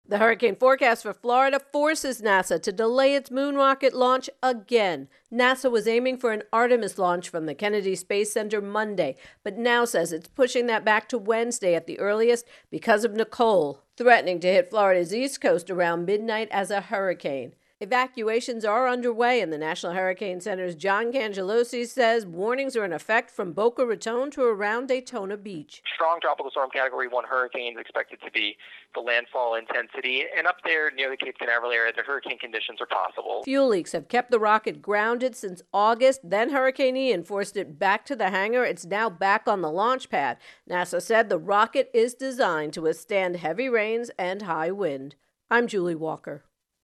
reports on NASA Moon Rocket